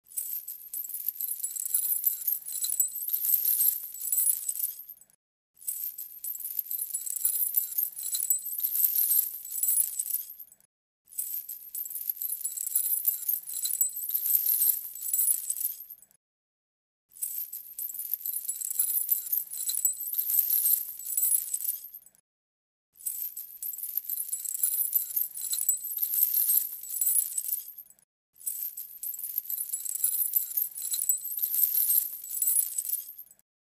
Light Chains Rattle Free Sound Effect.mp3